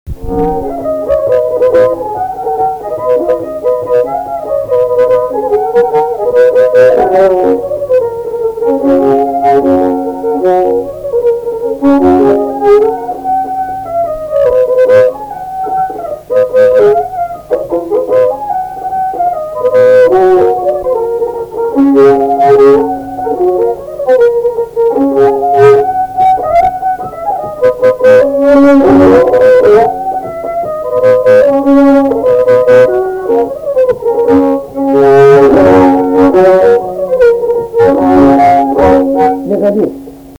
Polka
šokis